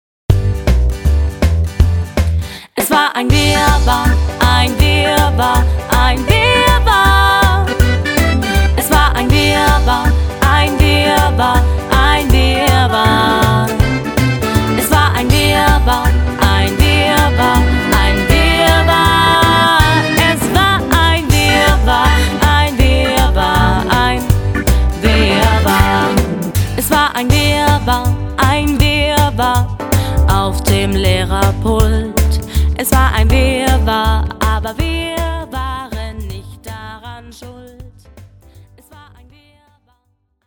Personalisierte Kinderlieder für den ganzen Tag.
nun rockt es